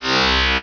beaming.wav